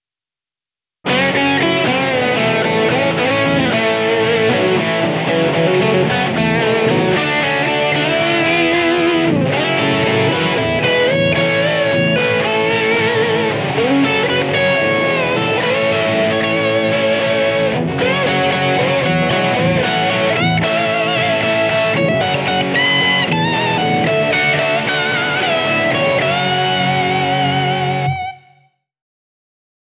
G：Sugi Guitars DS496 / Amp：Crunch / PU：Hum
OD9Pro+のクリーミーなドライブを加え、70年代ハードロックに代表される
滑らかで中音域に伸びのあるチューブ・ドライブ・サウンドを作り出します。
Creates smooth and lustrous mid-range,which is reminiscent of 70's hard rock.
Sugi Guitars DS496  Marshall JMP-1
MAXON_OD9PRO+_No.3_Classic_Drive.wav